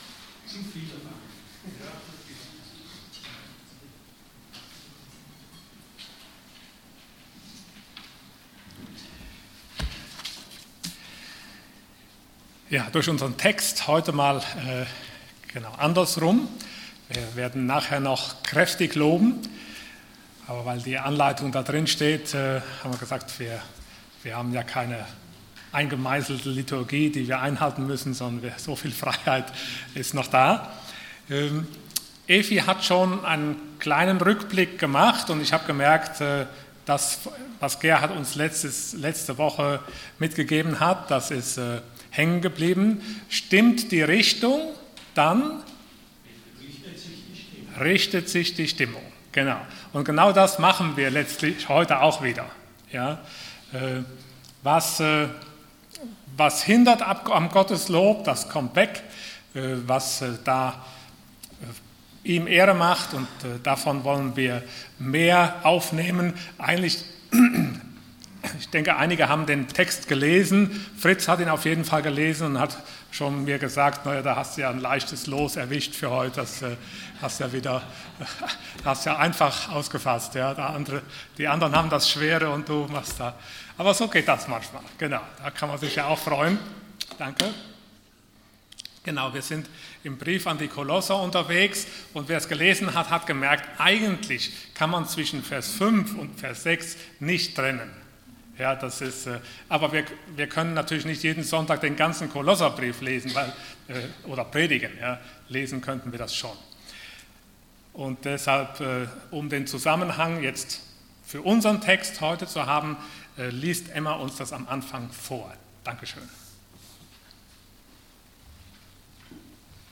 Passage: Kolosser 3,6-17 Dienstart: Sonntag Morgen